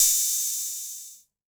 6HH OP 2.wav